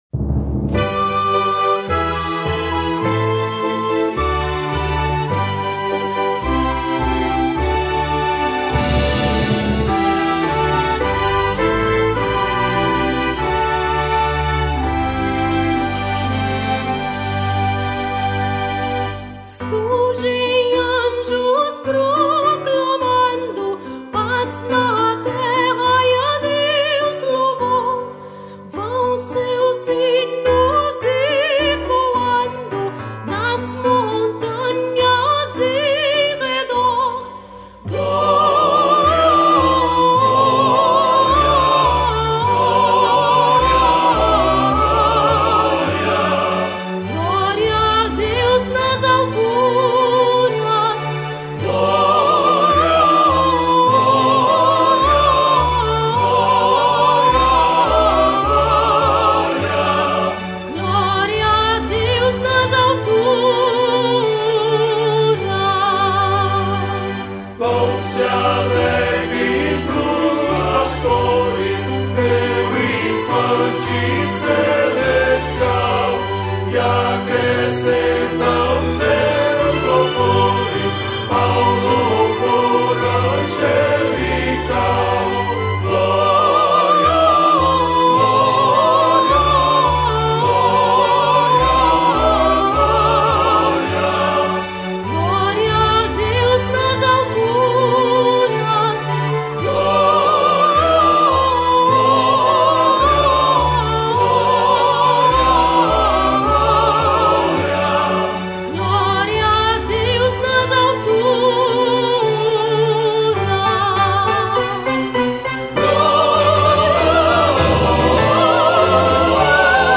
1051   02:36:00   Faixa:     Canção Religiosa